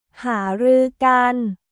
หารือกัน　ハールー　ガン